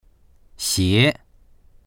xié 3
xie2.mp3